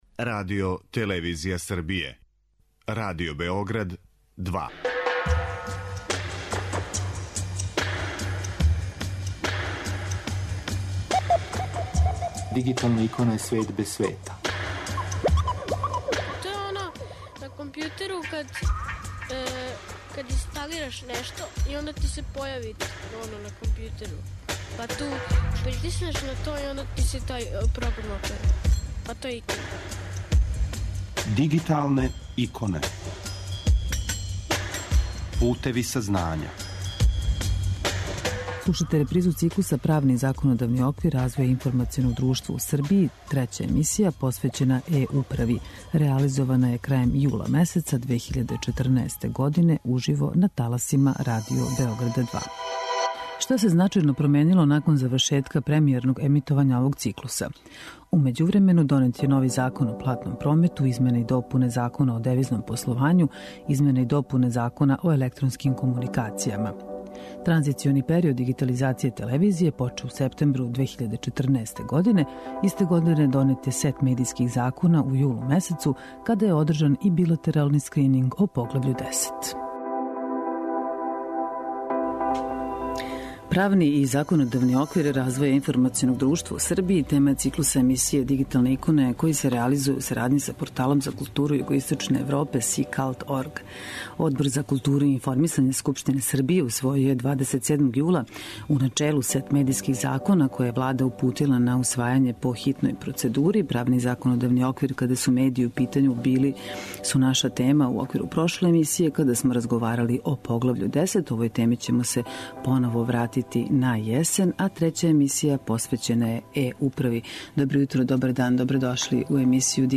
Слушате репризу циклуса 'Правни и законодавни оквир развоја информационог друштва у Србији'.
Трећа емисија, посвећена е-управи, реализована је крајем јула месеца 2014. године, уживо, на таласима Радио Београда 2.